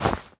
foley_object_grab_pickup_rough_03.wav